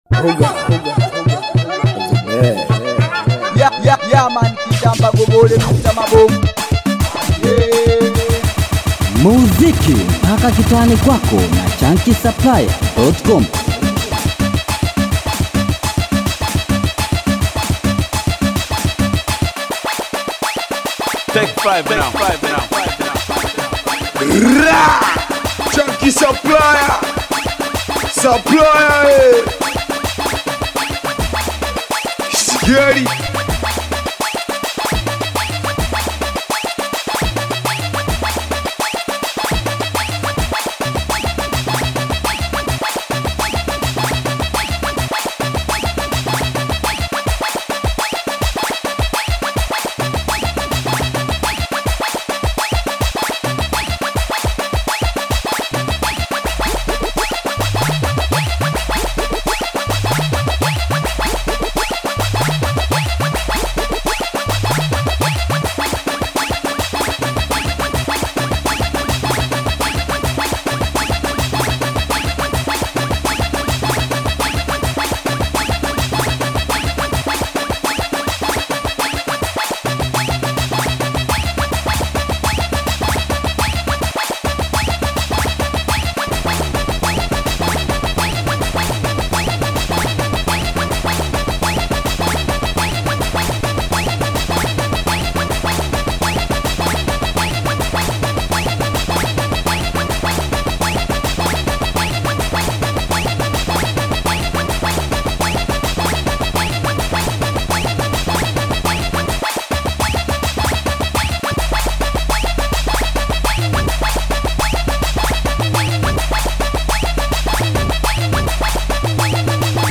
BITI SINGELI